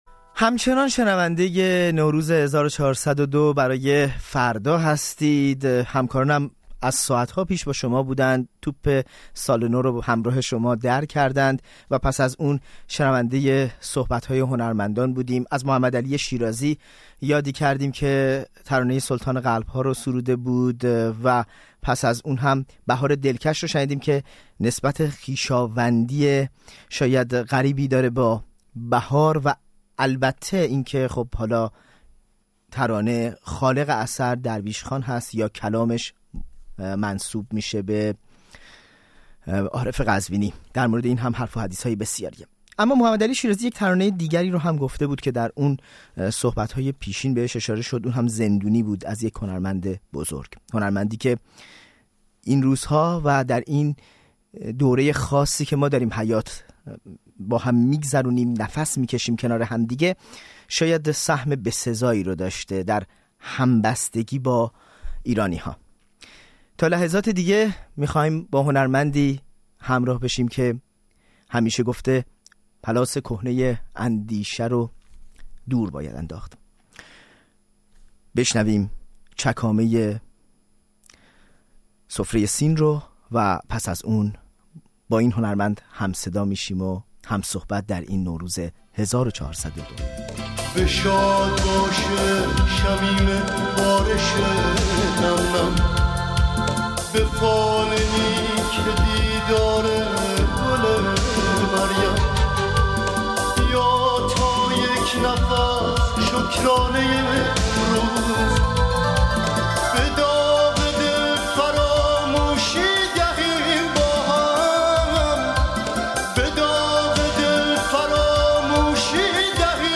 گفت‌وگوی نوروزی با داریوش